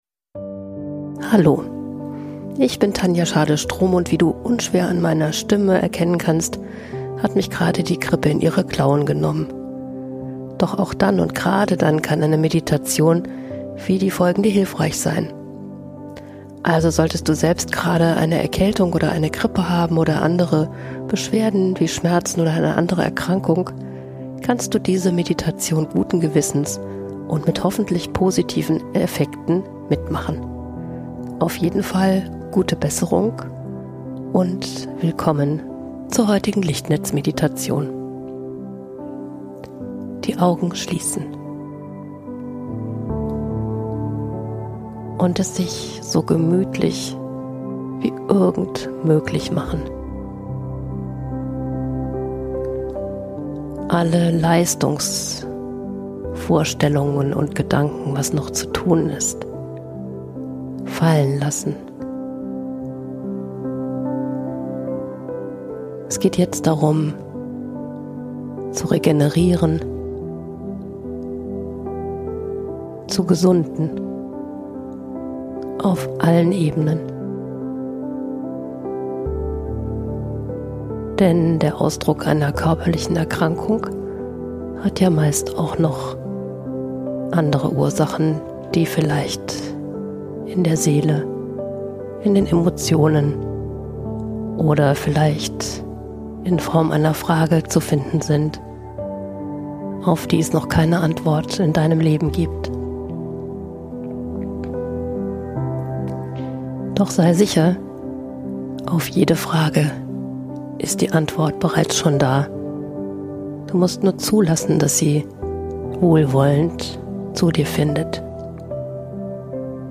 Diese SOS-Heilmeditation begleitet dich sanft durch eine Phase, in der dein Körper besondere Fürsorge braucht. Tiefe Entspannung Sanfte Heilimpulse Begleitende Musik Verbindung mit dem kosmischen Heiler Auch als unterstützende Ergänzung zu medizinischer Behandlung.